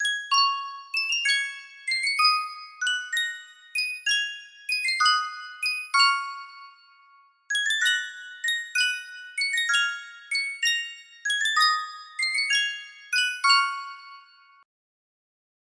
Sankyo 12 Note Music Box - A Bird in a Gilded Cage TSS music box melody
Full range 60